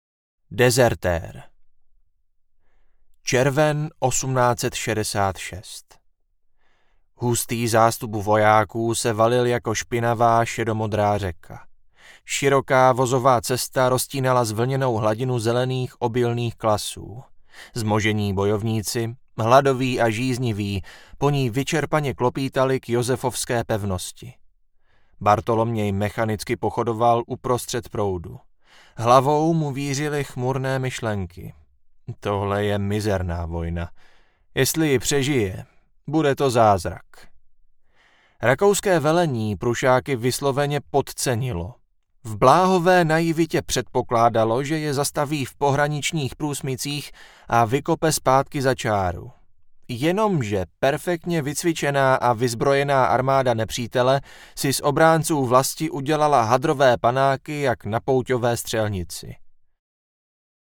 Interpret: Libor Böhm